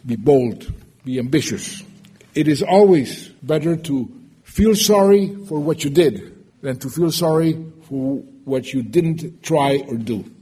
His lecture, named “From Hawk to Dove,” was delivered in front of a packed Forum Hall in the K-State Student Union and reflected on Santos’s experiences with war and peace and, at times, waging war to bring about peace.